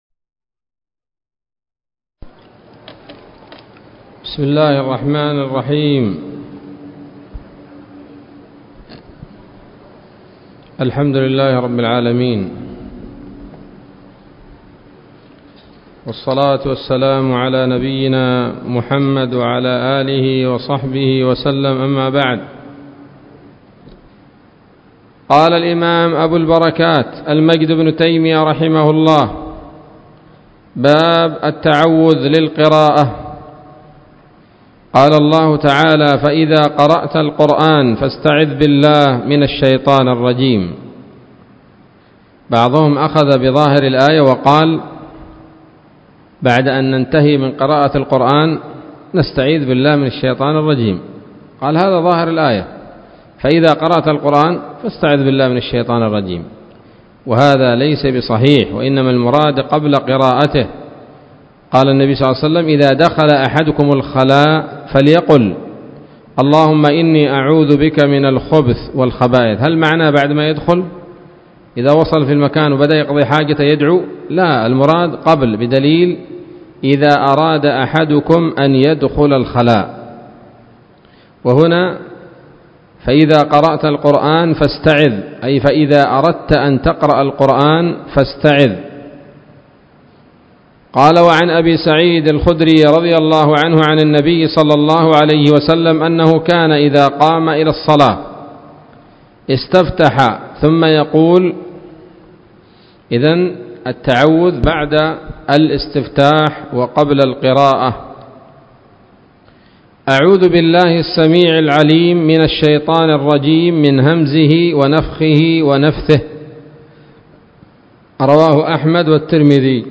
الدرس التاسع عشر من أبواب صفة الصلاة من نيل الأوطار